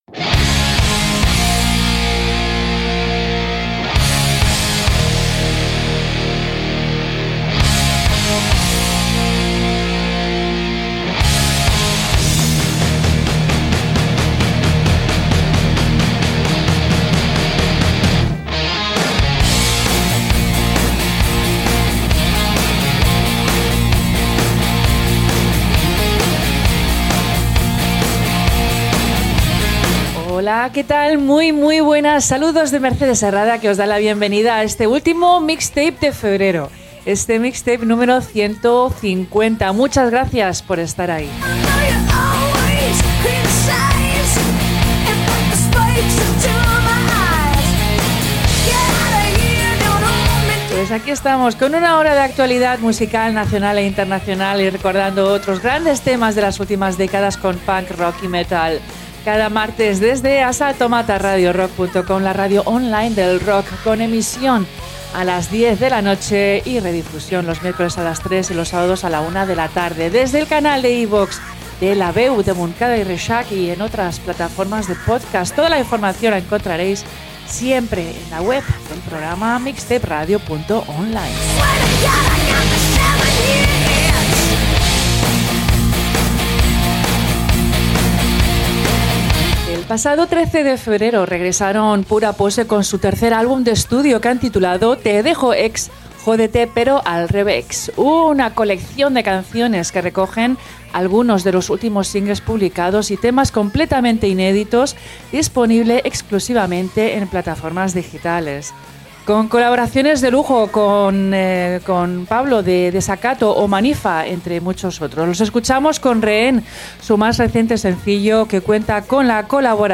Punk, Rock & Metal Radioshow